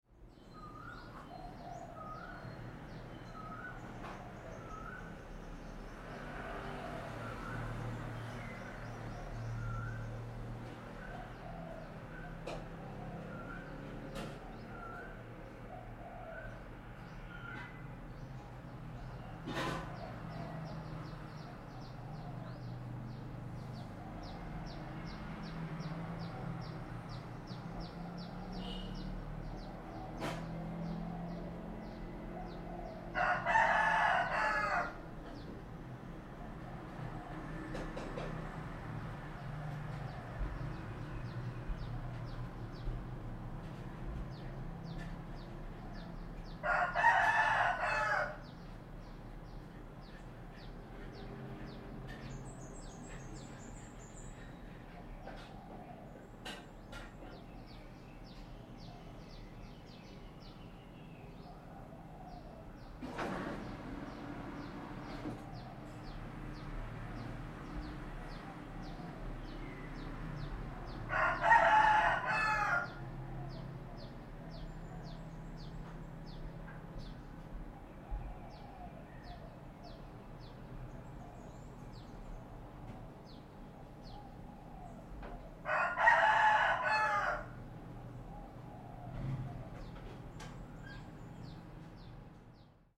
جلوه های صوتی
دانلود صدای آواز خروس در صبح بر روی ایوان از ساعد نیوز با لینک مستقیم و کیفیت بالا